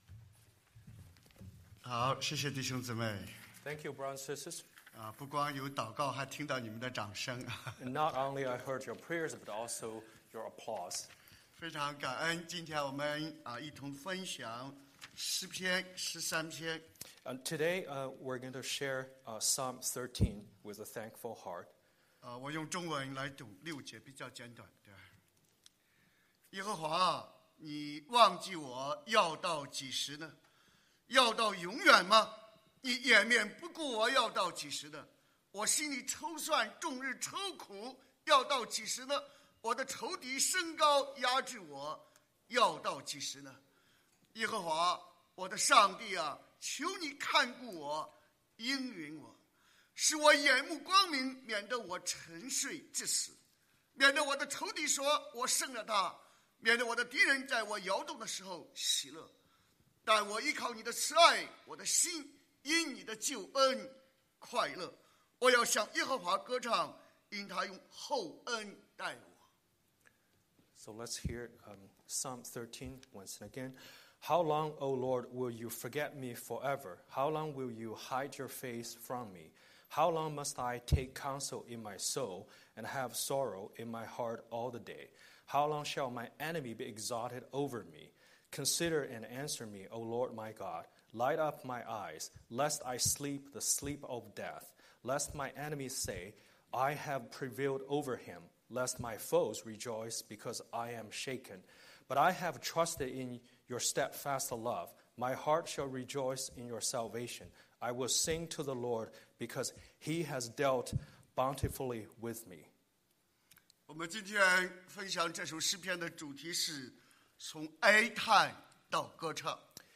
Scripture: Psalm 13:1–6 Series: Sunday Sermon